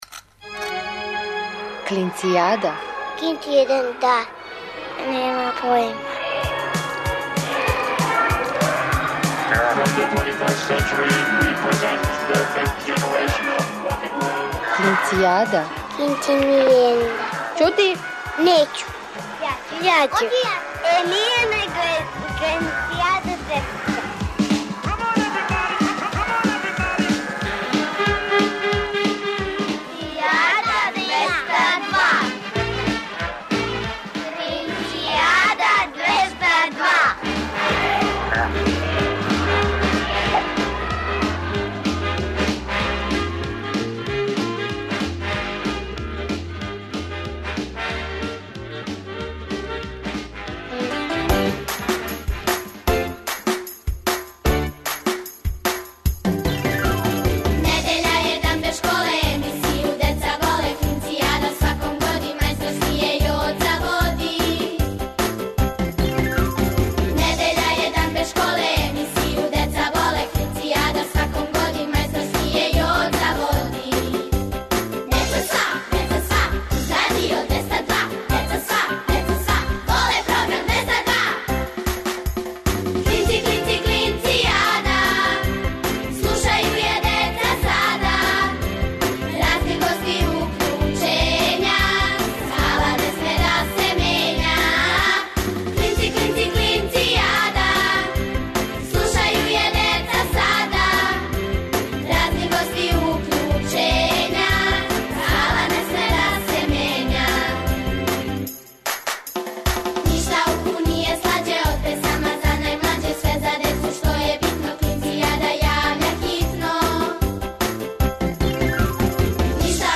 Сваке недеље уживајте у великим причама малих људи, бајкама, дечјим песмицама.